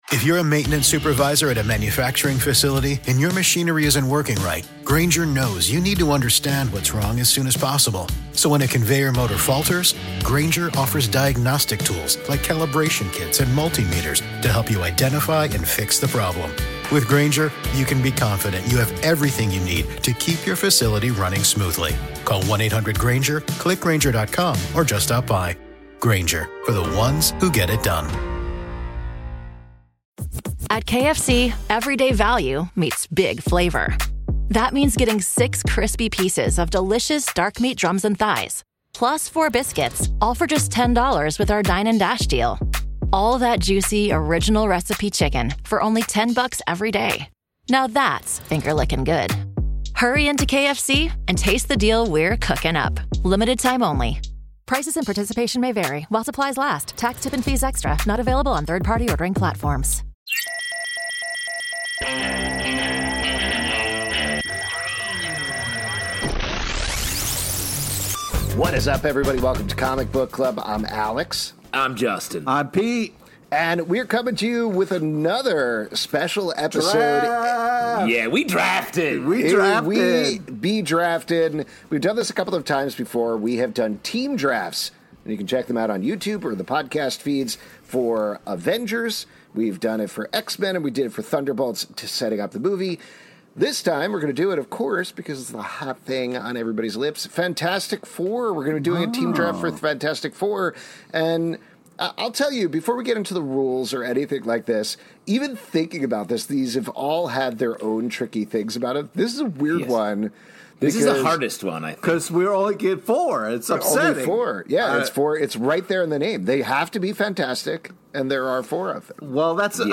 On this week's live show, guests Dave Hill (Oni Press "Dark Regards") + Greg Weisman (Dynamite "Gargoyles: Demona")!SUBSCRIBE ON RSS, APPLE, SPOTIFY, OR THE APP OF YOUR CHOICE.